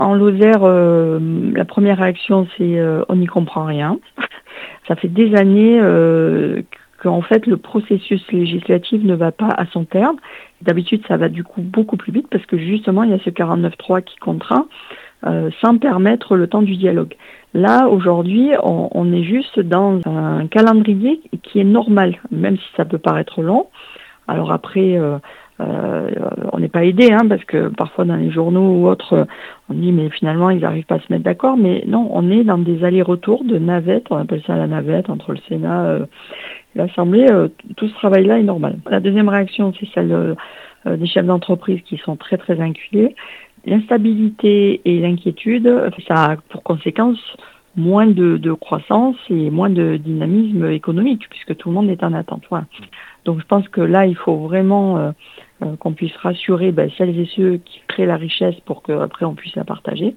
INTERVIEW. Budget de la sécu : Sophie Pantel votera pour